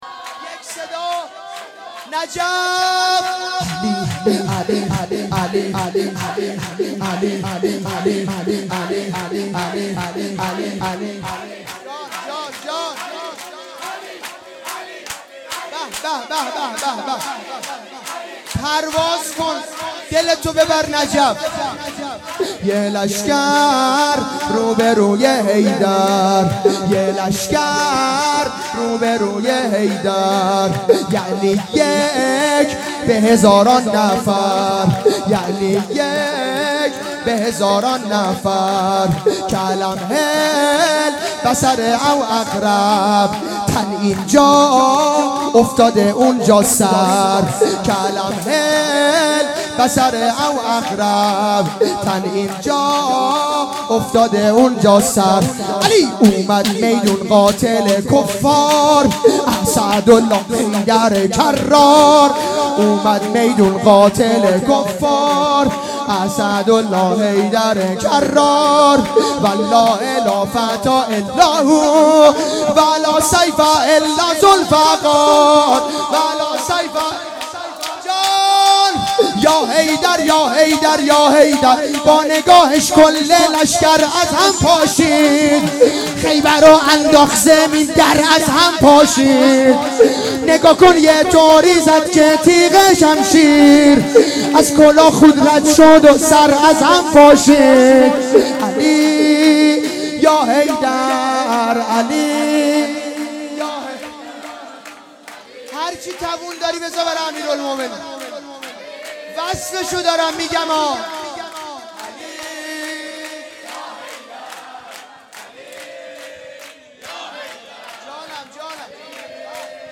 جشن ولادت پیامبر اکرم (ص) و امام صادق علیه السلام